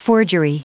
Prononciation du mot forgery en anglais (fichier audio)
Prononciation du mot : forgery